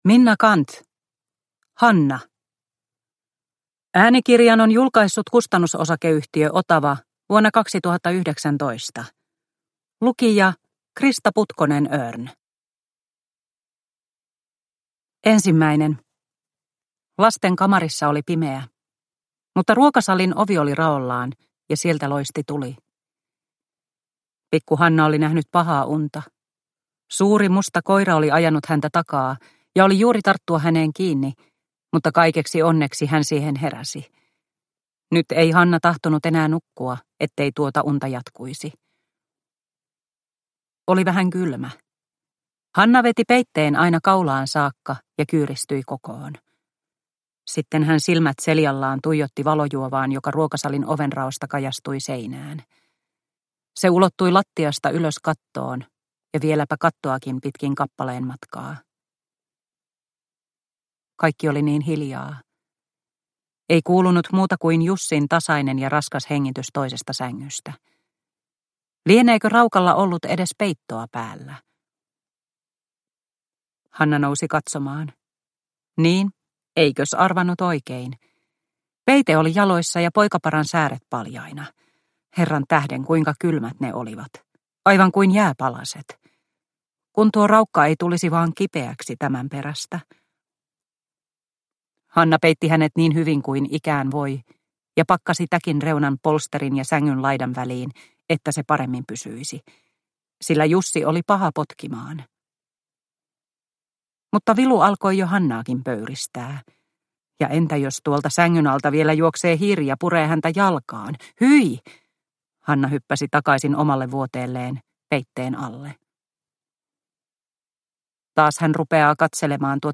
Hanna – Ljudbok – Laddas ner